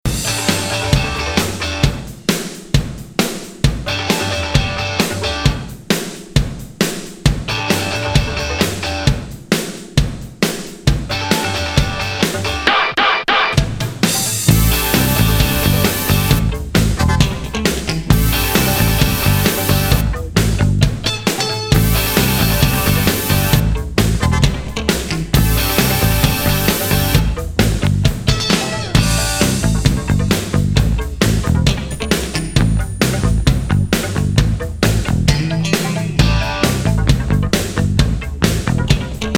込み上げバレア・アンビエント・インスト・ロック
Japanese Funk / Soul, Disco ♪LISTEN LABEL/PRESS